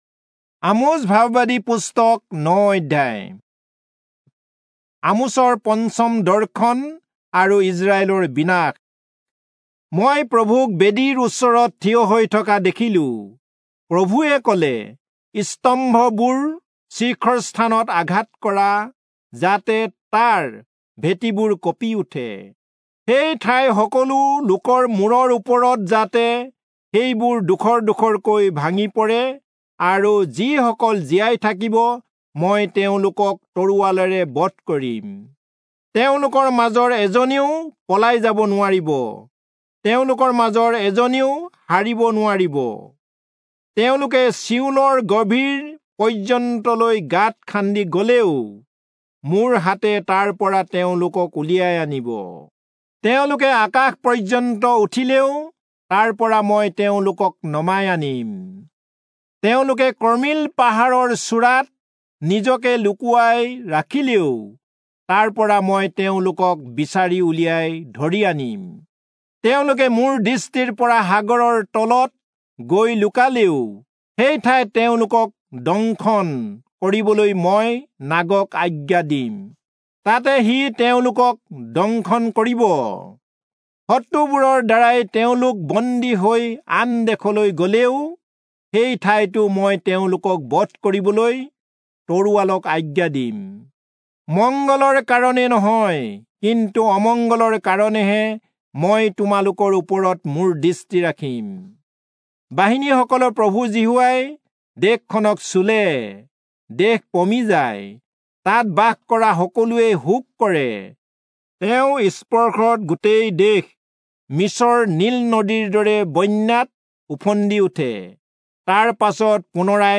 Assamese Audio Bible - Amos 4 in Knv bible version